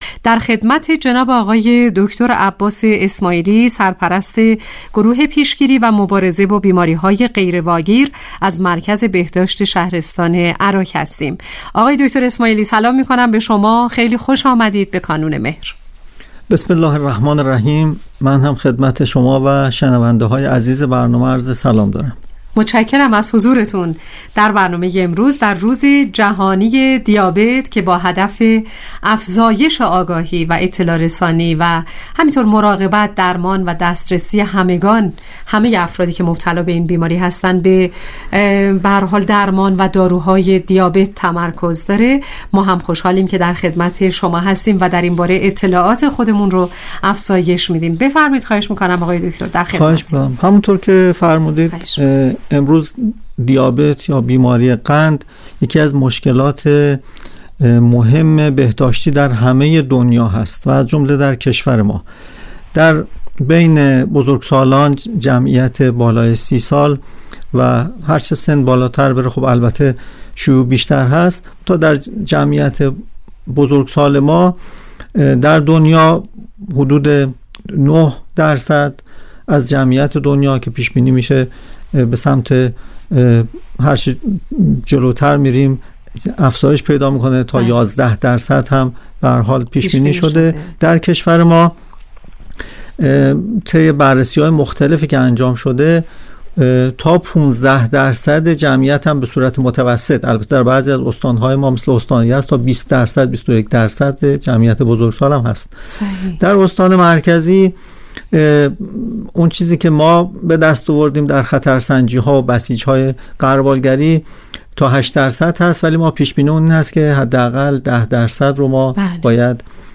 برنامه رادیویی کانون مهر